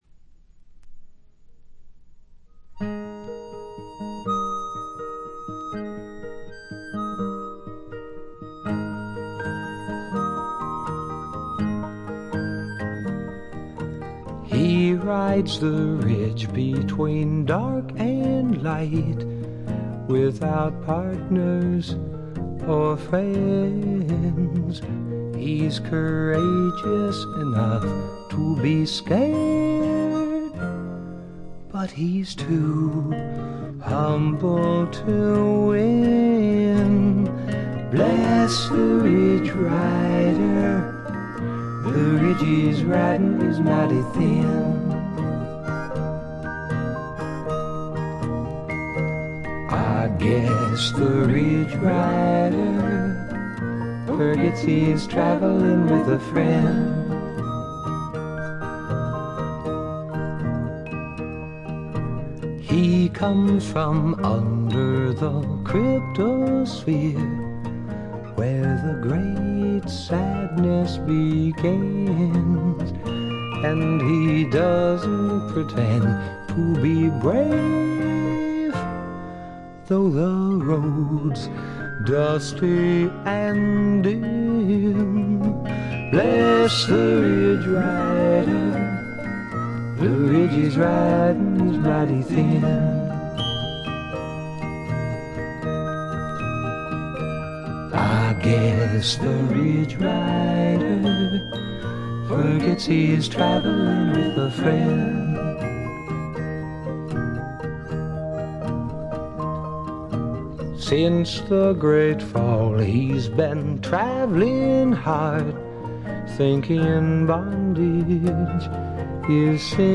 ※A4のイントロ部分まで。ノイズの状況をご確認ください。
Vocals